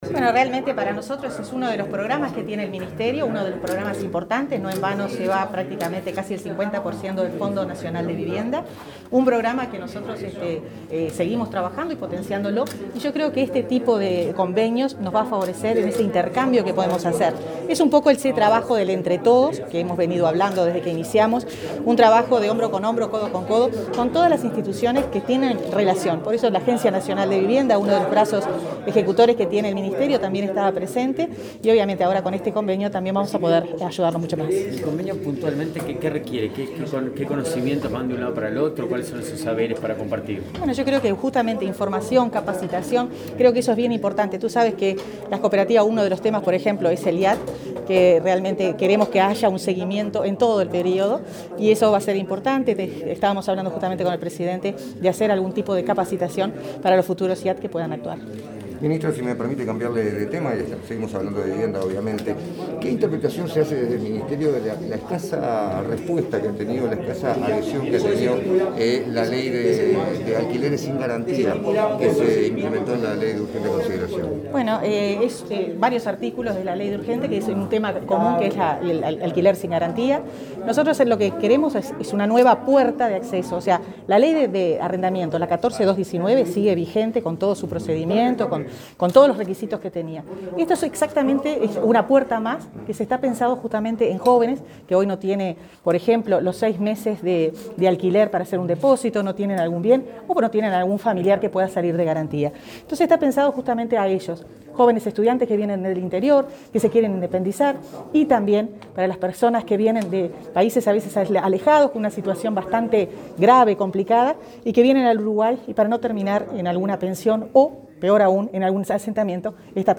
Declaraciones a la prensa de la ministra de Vivienda y Ordenamiento Territorial, Irene Moreira